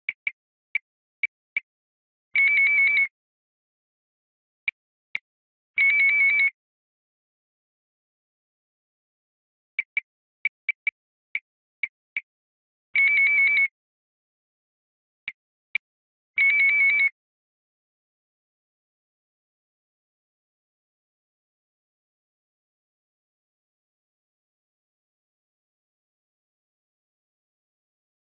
sfx_eurn_unedited.ogg